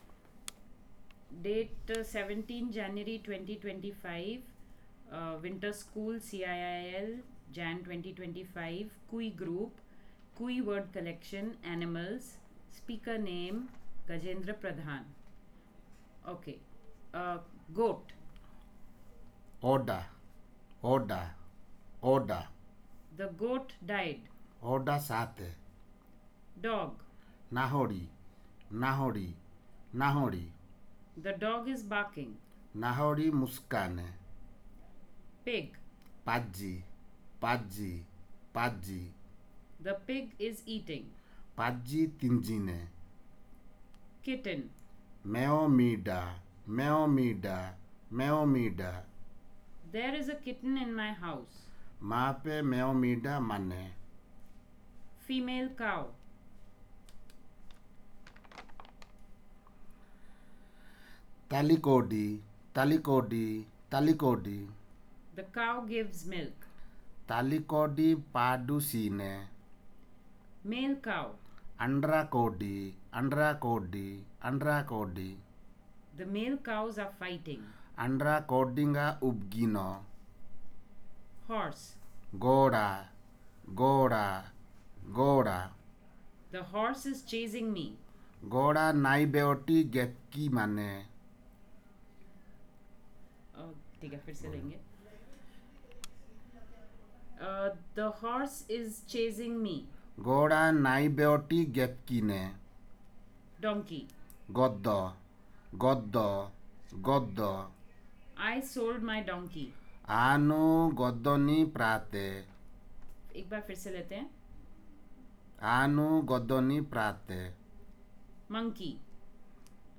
Elicitation of vocabulary on the domain of domestic animals